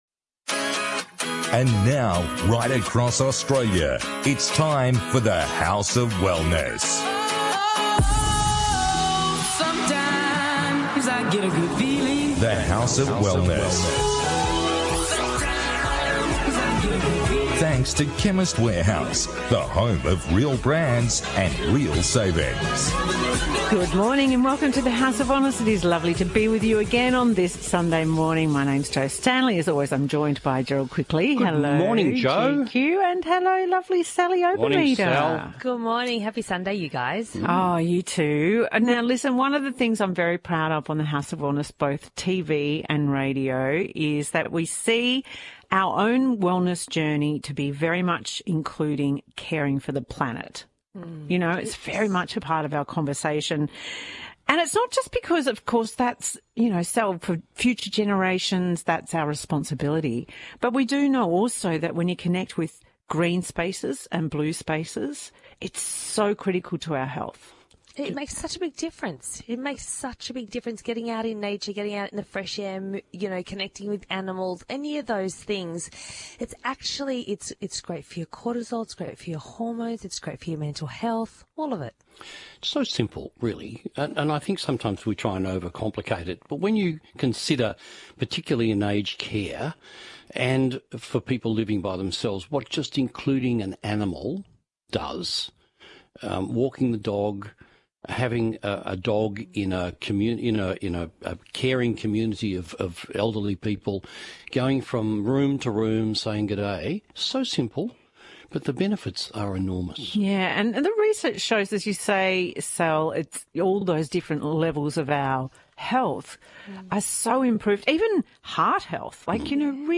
On this week’s The House of Wellness radio show, the team discusses: